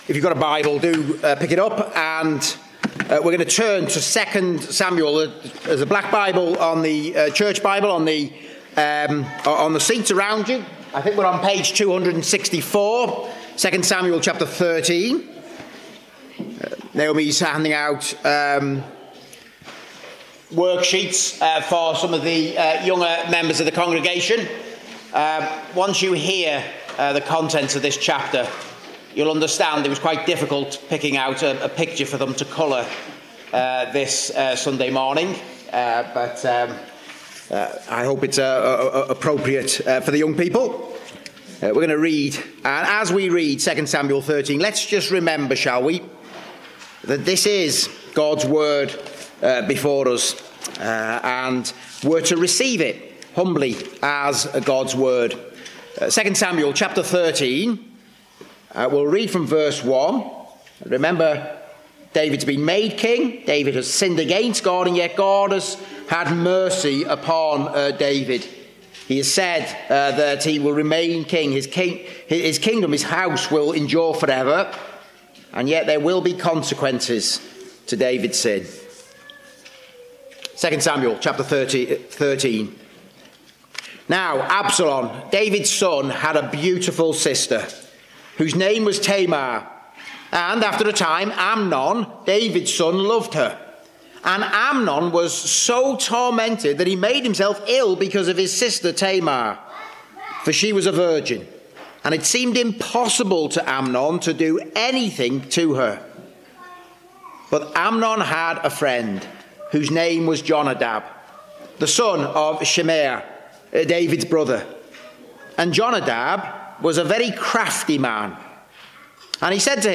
2 Samuel 13:1-39 Service Type: Preaching In the midst of evil and darkness God speaks.